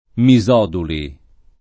vai all'elenco alfabetico delle voci ingrandisci il carattere 100% rimpicciolisci il carattere stampa invia tramite posta elettronica codividi su Facebook Misoduli [ mi @0 duli ] n. pr. m. pl. — accademia di Prato — accentaz. sdrucciola alla graca, come in Teodoli , Teodulo